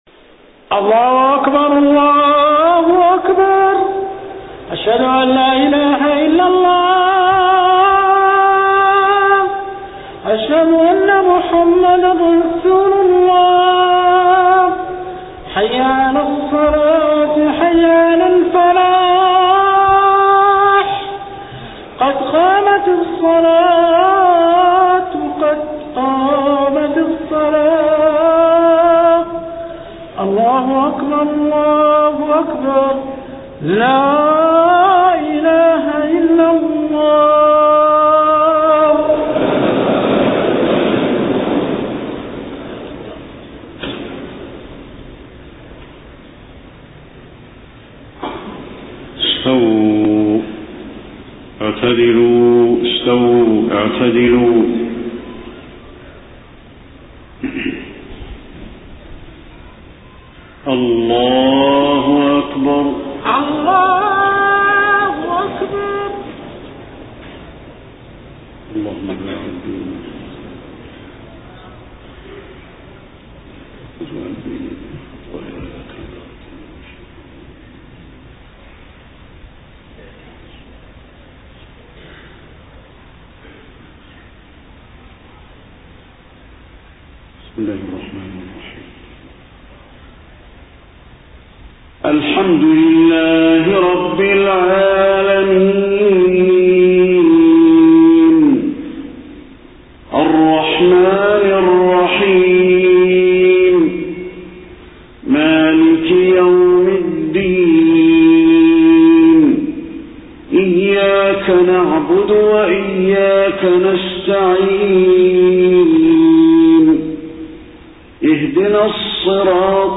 صلاة العشاء 29 صفر 1431هـ فواتح سورة الملك 1-15 > 1431 🕌 > الفروض - تلاوات الحرمين